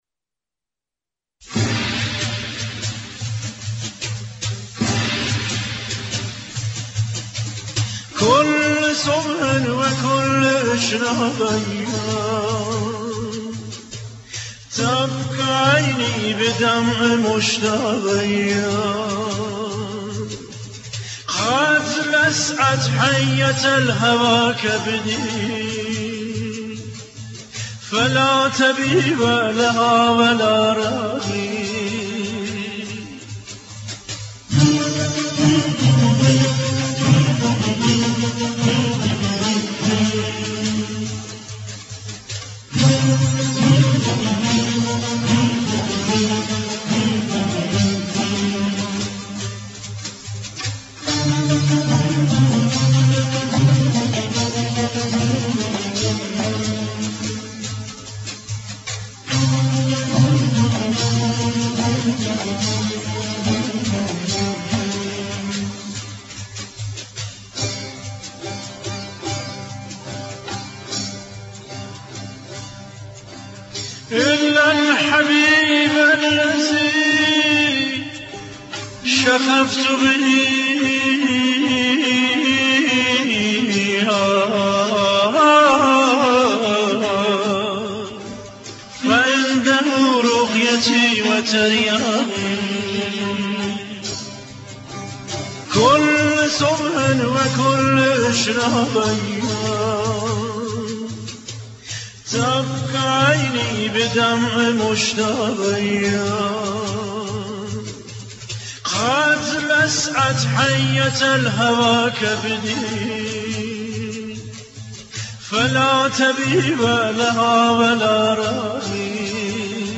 این قطعه به دو زبان فارسی و عربی خوانده شده